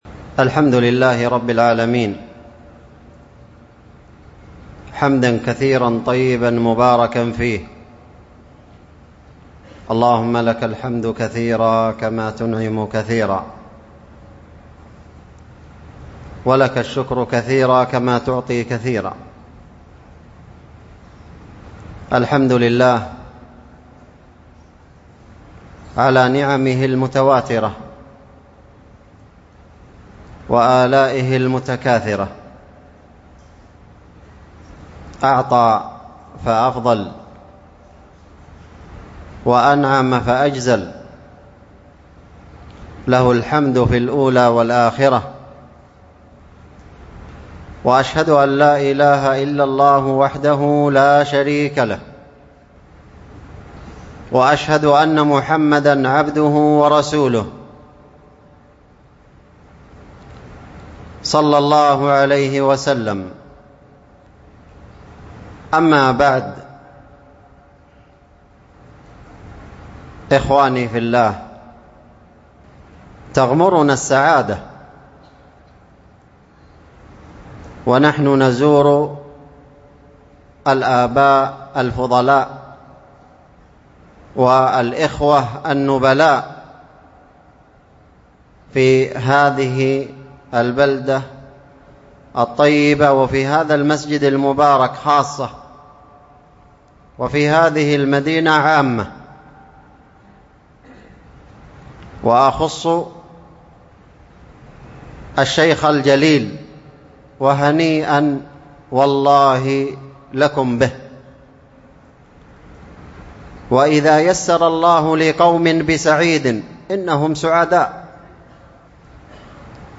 محاضرة في بويش بالمكلا حول تعريف التوحيد وبيان أهميته، وفضائله، وضرورة العناية به ، وكونه أولا في الدعوة والتعليم .